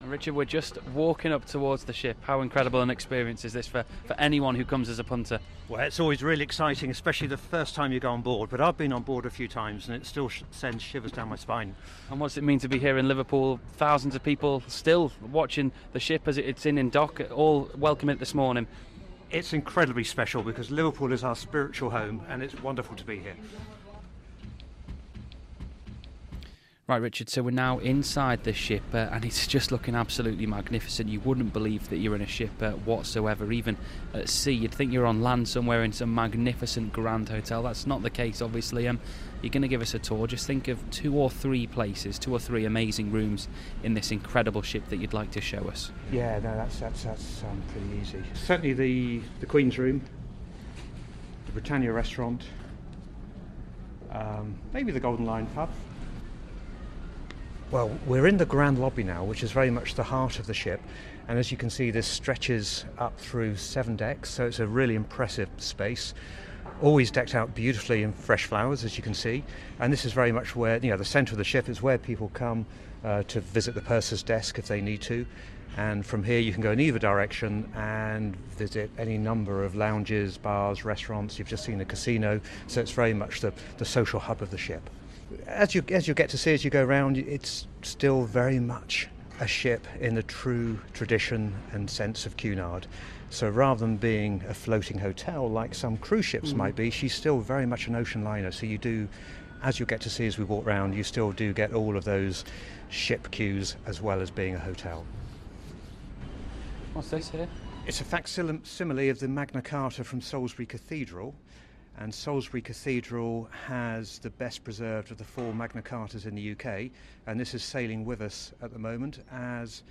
With the Three Queens in Liverpool this weekend for Cunard's 175th birthday celebrations, Radio City was given an exclusive behind the scenes tour of the Queen Mary 2, the flagship liner